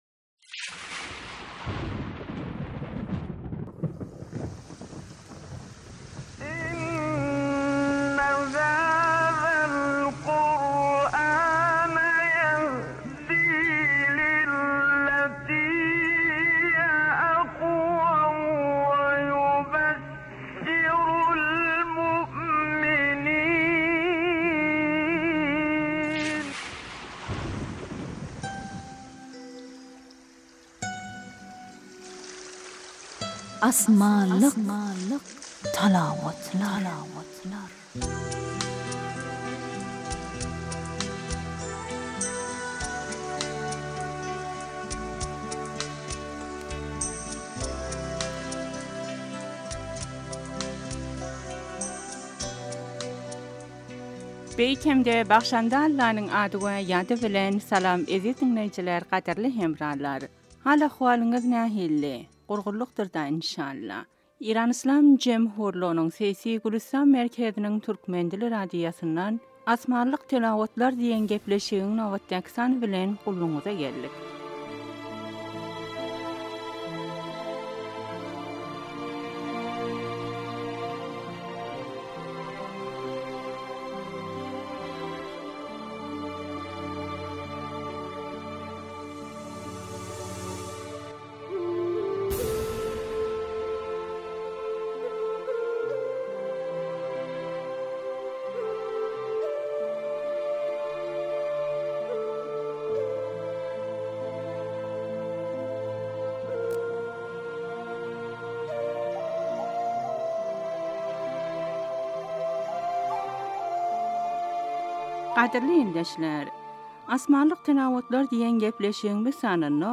Asmanlik talawatlar
Asmanlik talawatlar : müsürli karilar